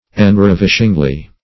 enravishingly - definition of enravishingly - synonyms, pronunciation, spelling from Free Dictionary Search Result for " enravishingly" : The Collaborative International Dictionary of English v.0.48: Enravishingly \En*rav"ish*ing*ly\, adv. So as to throw into ecstasy.
enravishingly.mp3